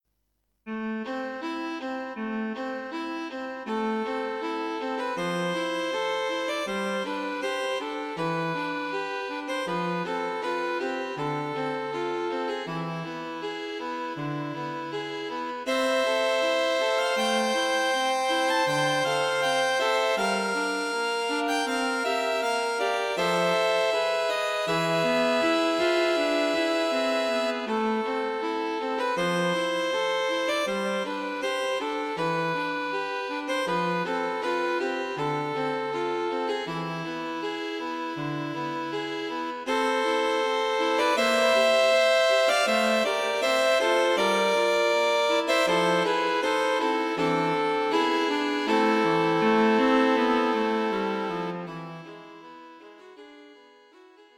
String Quartet
in F-sharp minor
This arrangement is for String quartet.